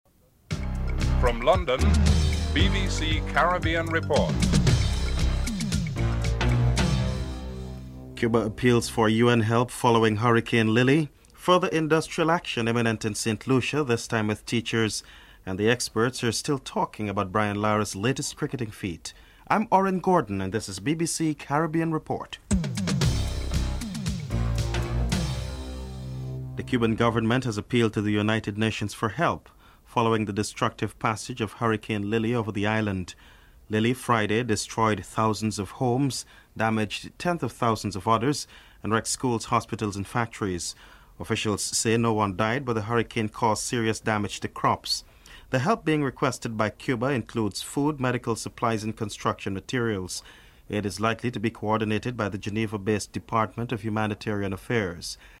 1. Headlines (00:00-00:26)